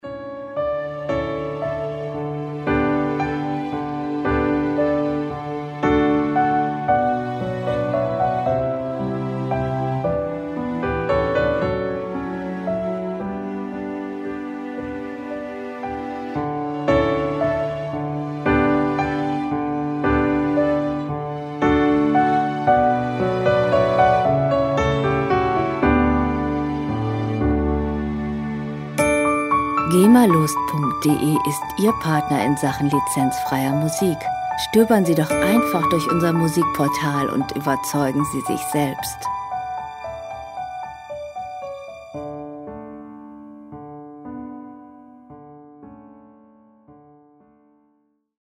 lizenzfreie Werbemusik für Imagefilme
Musikstil: Soundtrack
Tempo: 114 bpm
Tonart: D-Dur
Charakter: verhalten, leicht
Instrumentierung: Flügel, Streicher, Holzbläser